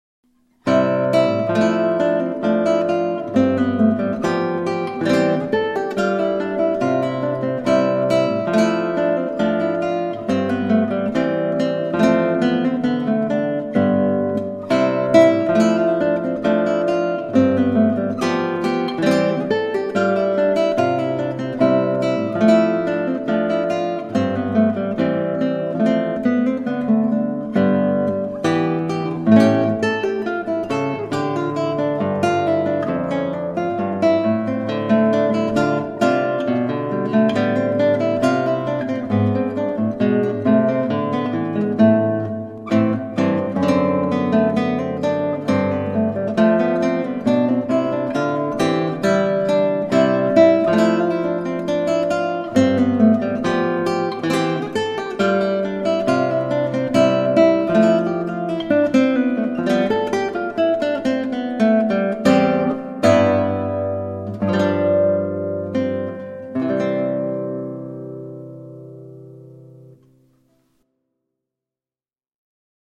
Classical Guitarist
This Renassiance dance makes a great processional. Moving effortlessly from voice to voice, the melody weaves a brief, but heart-felt love story.